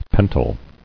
[pen·tyl]